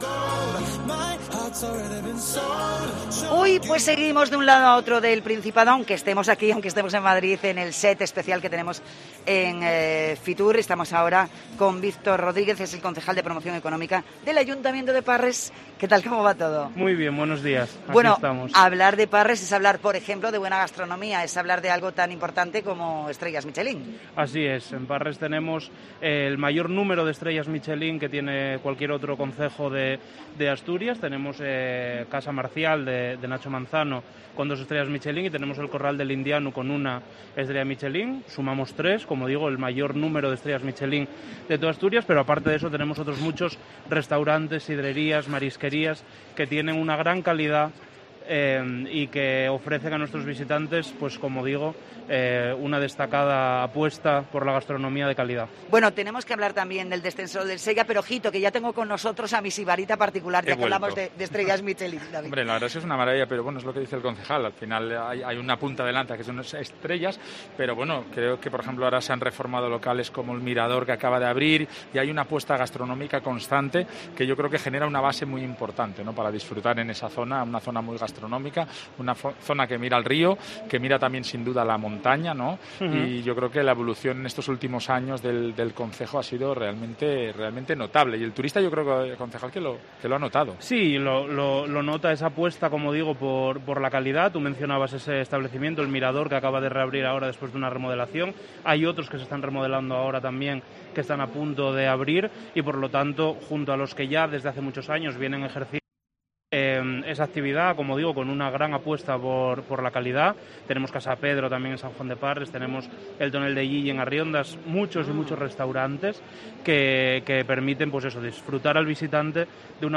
Entrevista al concejal de Promoción Económica de Parres, Víctor Rodríguez